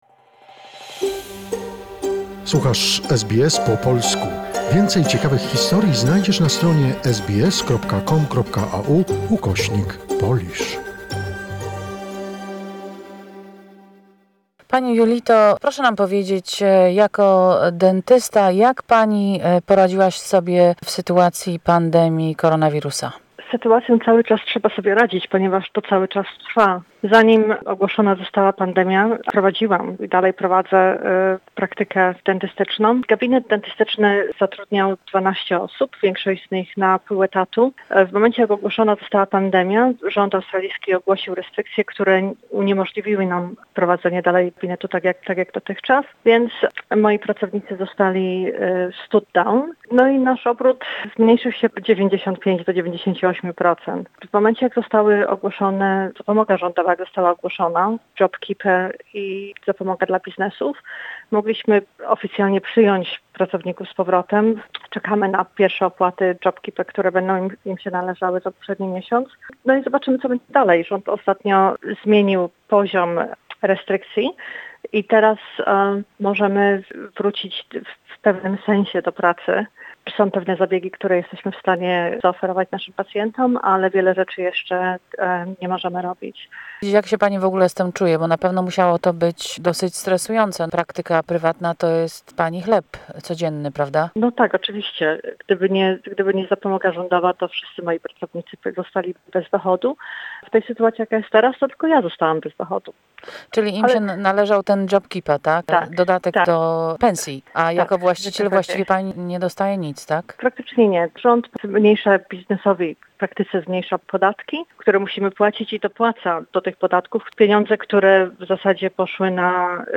How are we coping with difficult restrictions and economic stagnation? Various people, a self-employed dentist, a working mother, a young woman on a student visa, and an elderly pensioner talk about their financial, life and family struggles during the coronavirus pandemic.